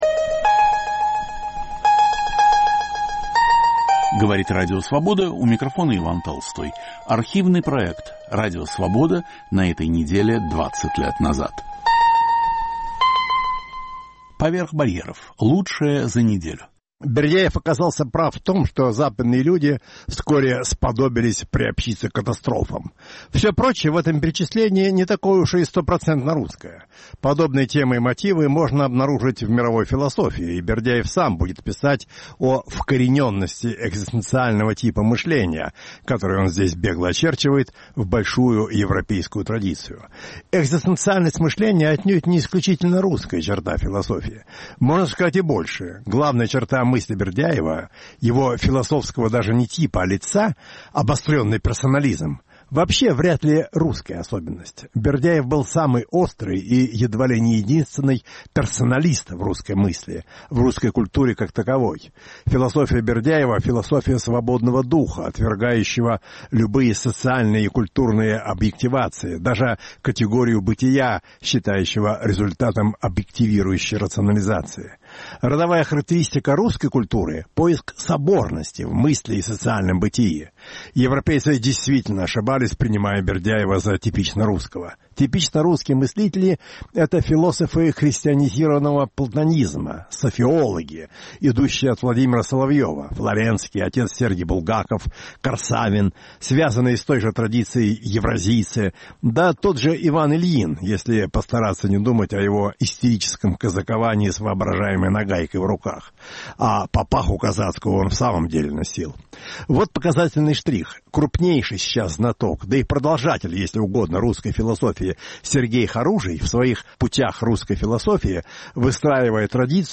Ведущий Алексей Цветков.